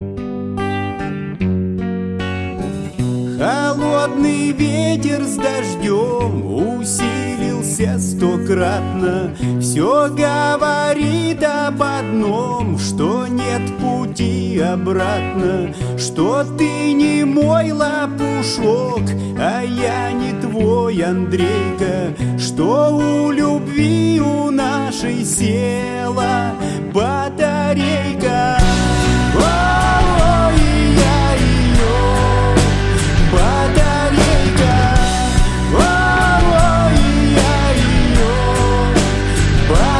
грустные
рок